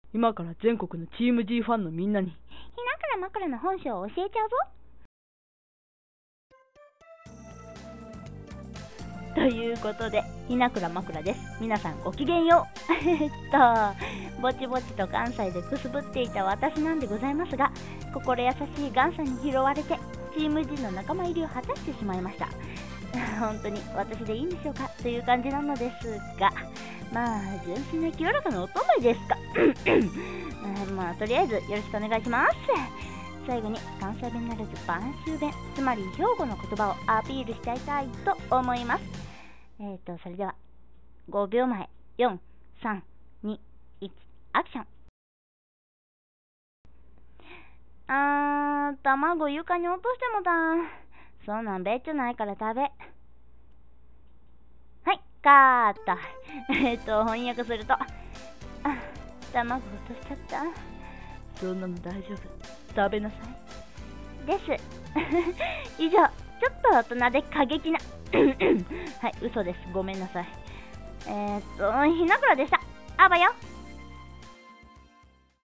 自己紹介ボイス
七色の声炸裂ですｗｗ